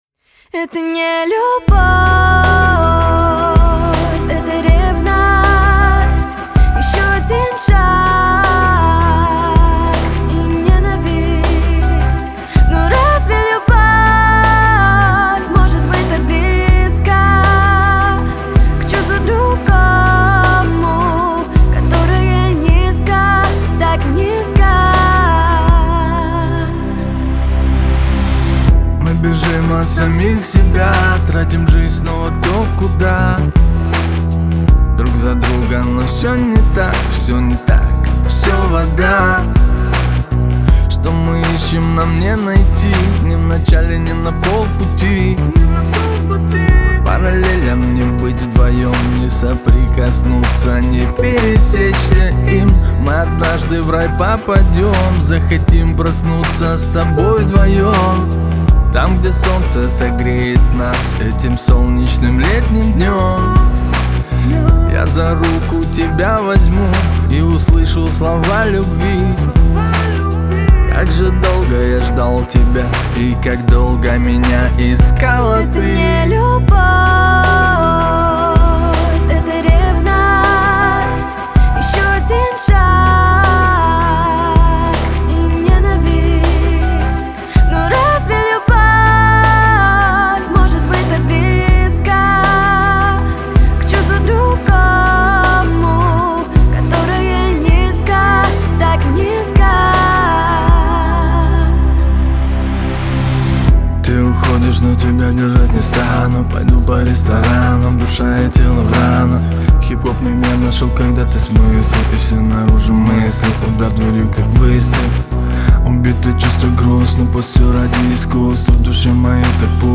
яркая композиция в жанре поп